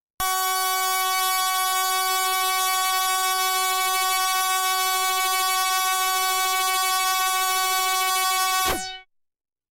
标签： F4 MIDI音符-66 雅马哈-CS-30L 合成器 单注 多重采样
声道立体声